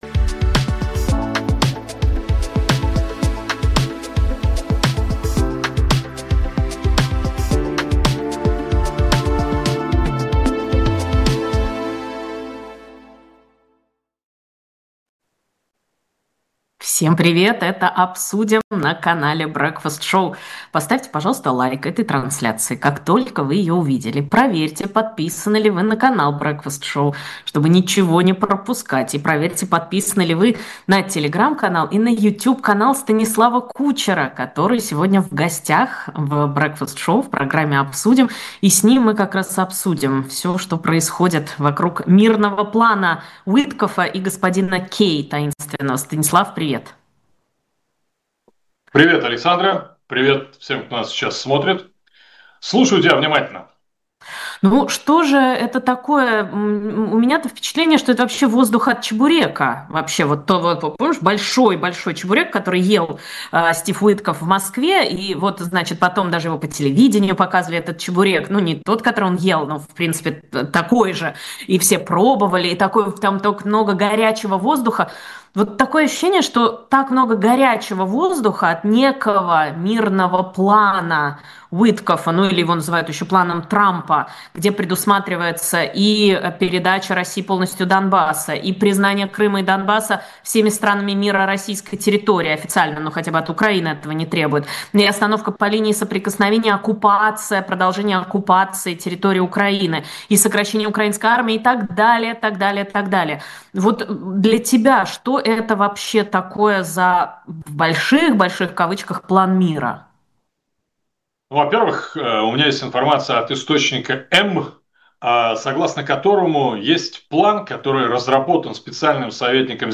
Станислав Кучер журналист